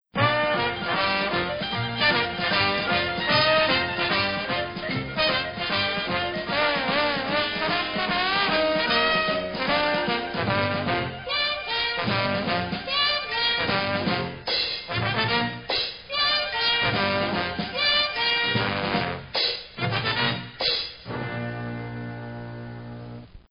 Theme Song